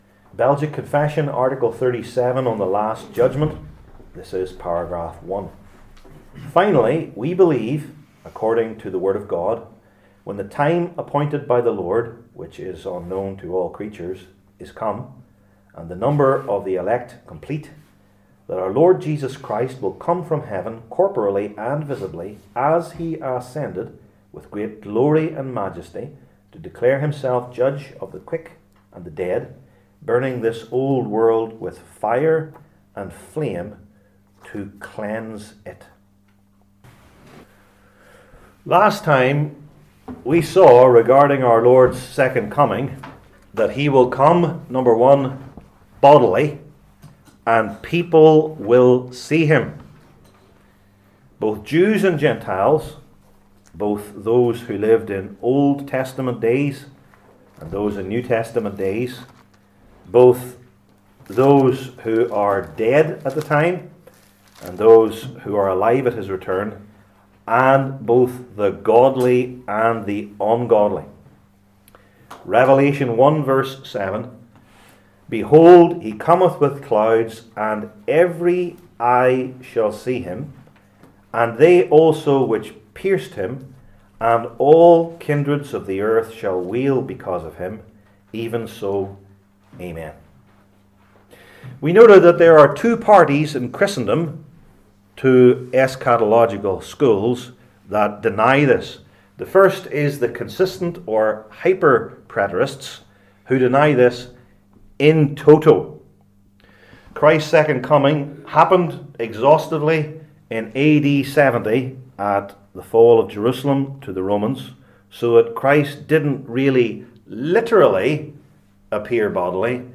Passage: Matthew 13:24-30, 36-43, 47-52 Service Type: Belgic Confession Classes